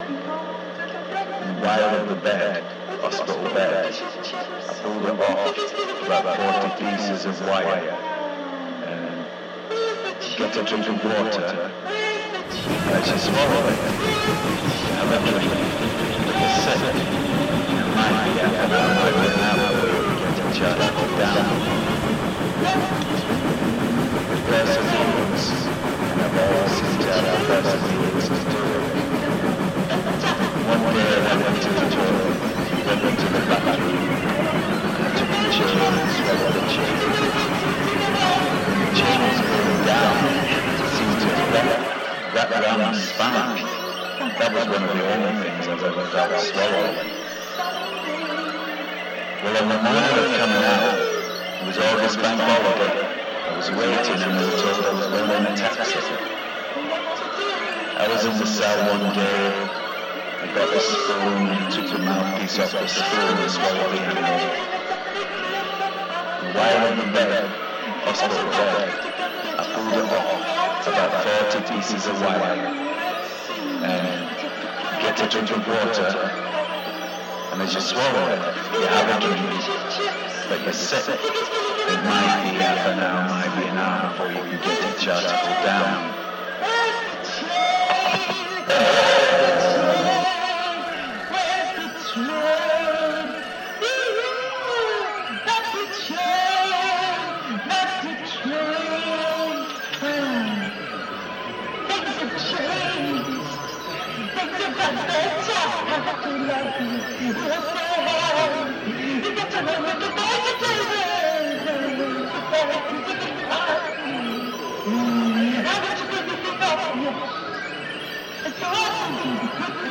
achieves levels of coruscating atonality
• Genre: Industrial / Experimental